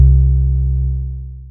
TM88 ThuggerSub808.wav